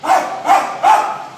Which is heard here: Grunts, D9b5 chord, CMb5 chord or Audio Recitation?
Grunts